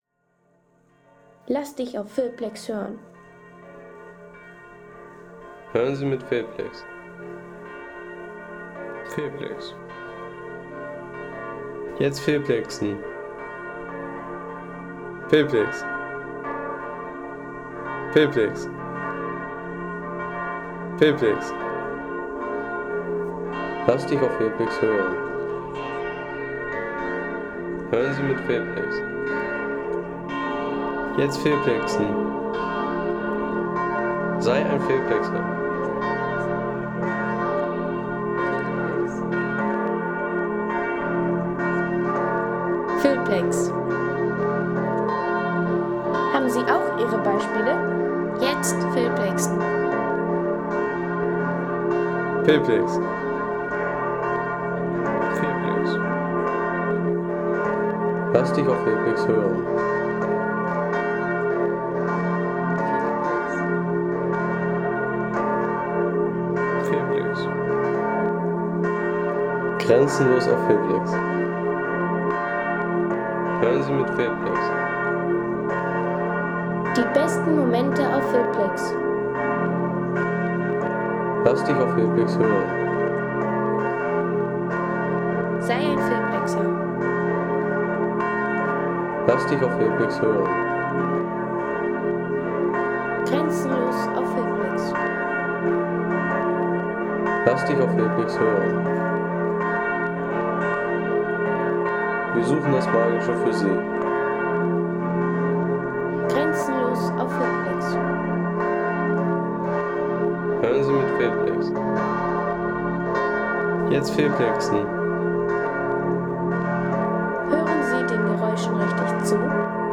Dresdner Hofkirche Glockengeläut | Feelplex
Ein Glockensound, der über Dresden hinwegzieht
Majestätisches Glockengeläut der Dresdner Katholischen Hofkirche mit Stadthall und historischer Atmosphäre.
Majestätisches Geläut der Dresdner Katholischen Hofkirche mit Hall über Stadt, Platz und Elbe.